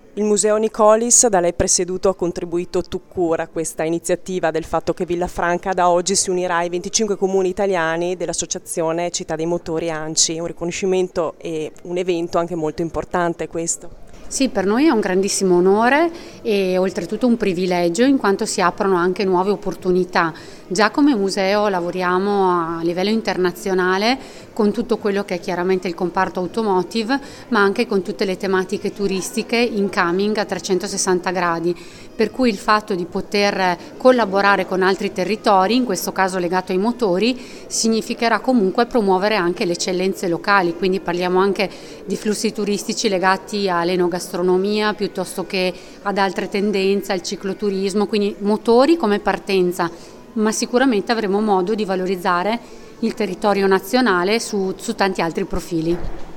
ha intervistato per noi: